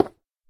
Minecraft / dig / stone4.ogg
stone4.ogg